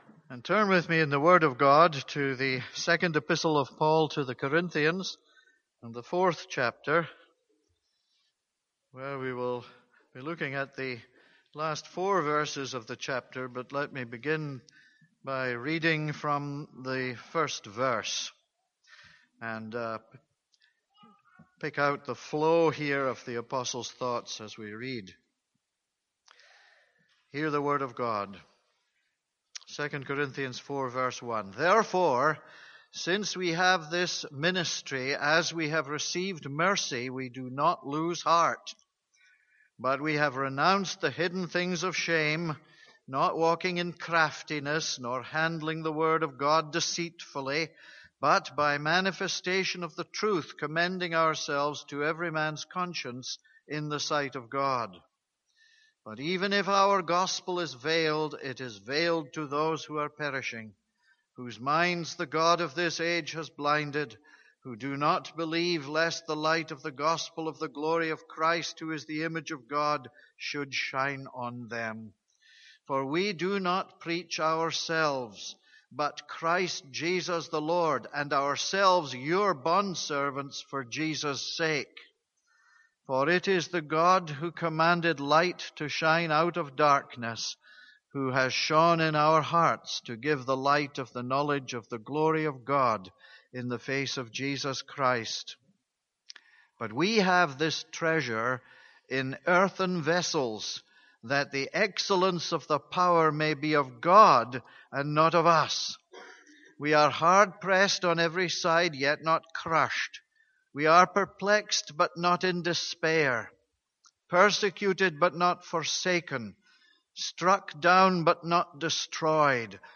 This is a sermon on 2 Corinthians 4:15-18.